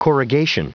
Prononciation du mot corrugation en anglais (fichier audio)
Prononciation du mot : corrugation